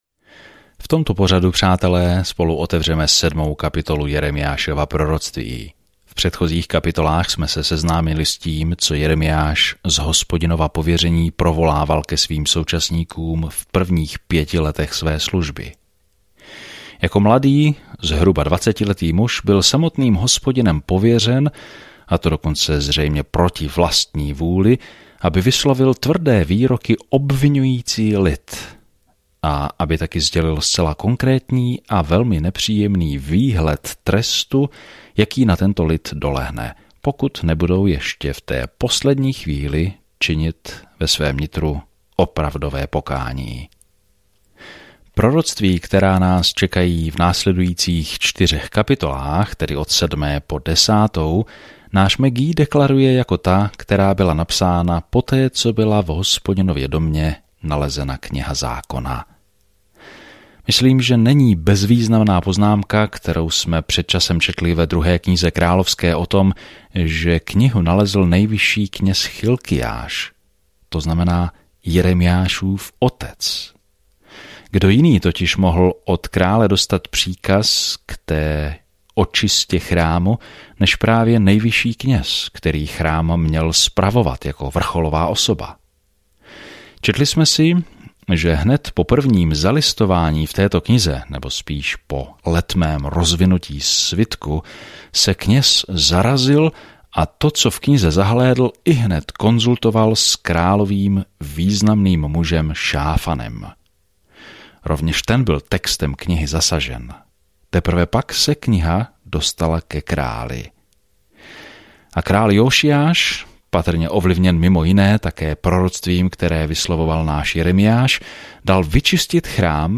Písmo Jeremiáš 7 Den 7 Začít tento plán Den 9 O tomto plánu Bůh si vybral Jeremiáše, muže něžného srdce, aby předal drsné poselství, ale lidé toto poselství nepřijímají dobře. Denně procházejte Jeremiášem, poslouchejte audiostudii a čtěte vybrané verše z Božího slova.